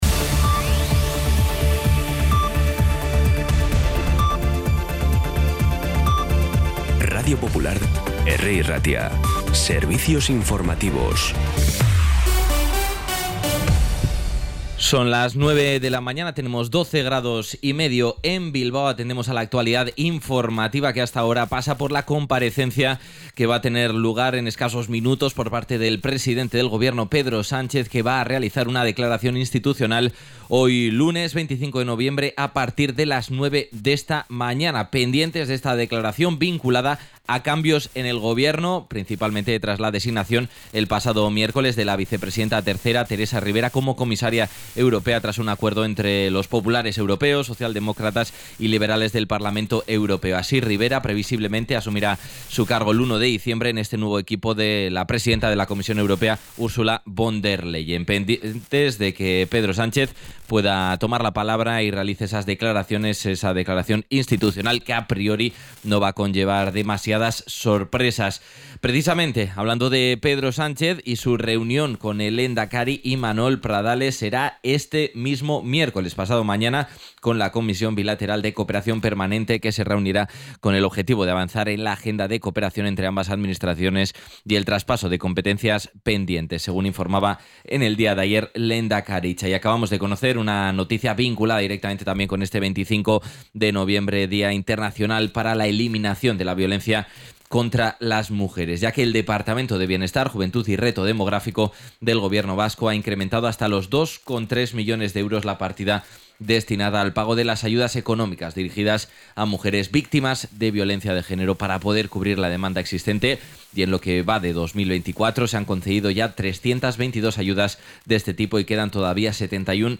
Las noticias de Bilbao y Bizkaia del 25 de noviembre a las 9